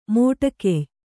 ♪ mōṭa key